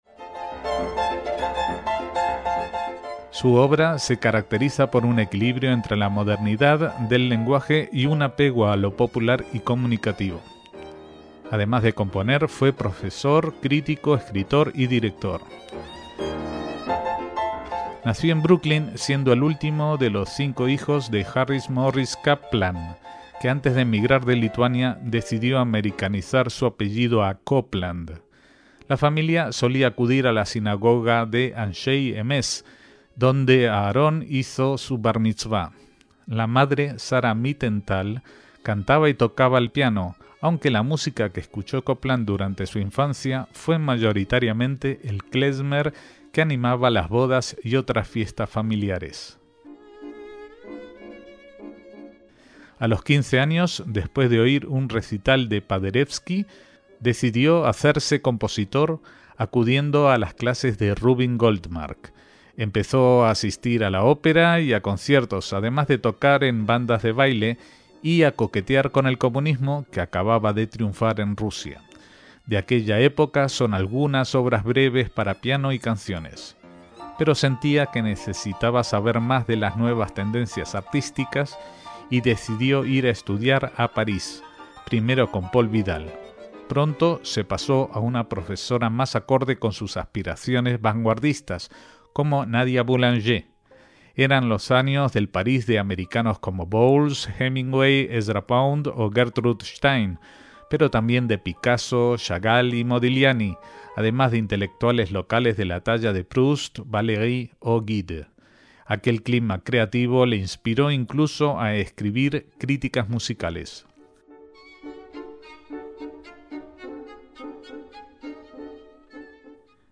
Comenzaremos con su Fanfarria para el hombre común (Fanfare for the Common Man en su original en inglés) para metales y percusión, obra compuesta en 1942 por encargo del director de orquesta Eugene Goossens, quien quiso iniciar los conciertos de la Orquesta Sinfónica de Cincinnati con fanfarrias escritas por distintos autores en homenaje a los combatientes aliados en la Segunda Guerra Mundial, entonces en curso.